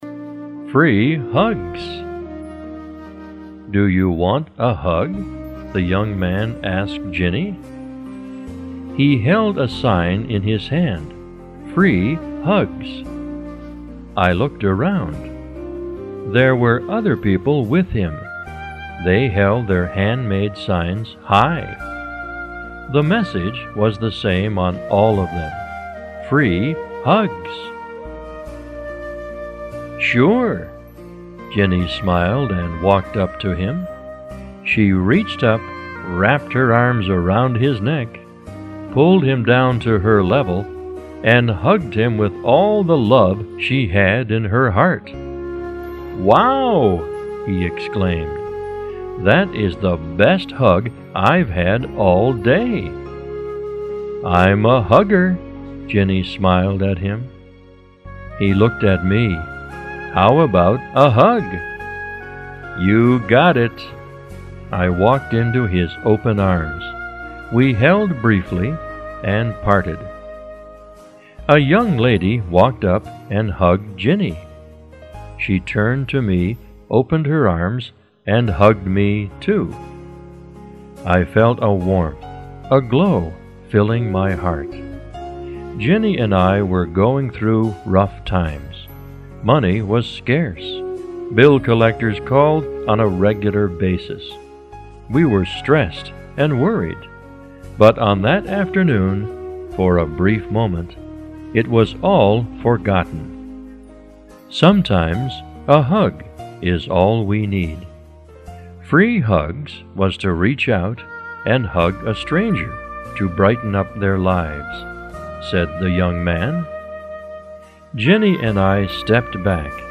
倾听一则故事，每则故事均由资深外教精心配音录制，深情的朗诵，搭配柔美乐音，让你在倾听如水般恬静流淌的英文的同时，放松心情、纾解压力。